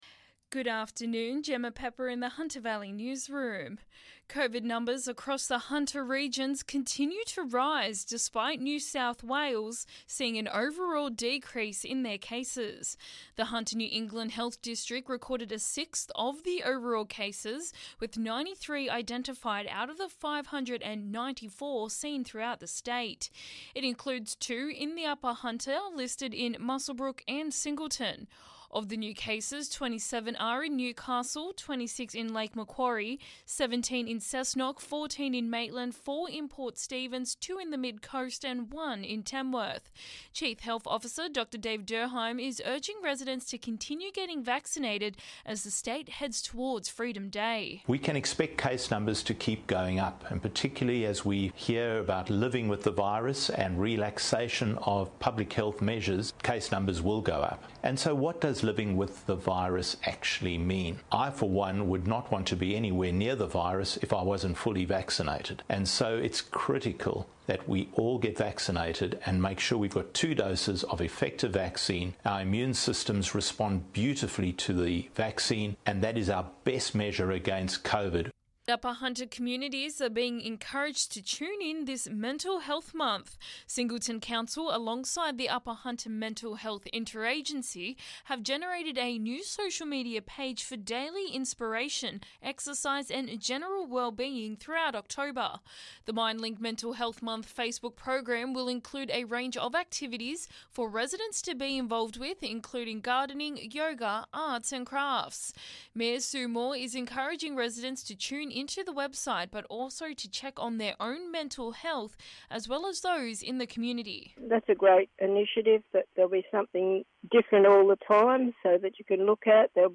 Listen: Hunter Local News Headlines 06/10/2021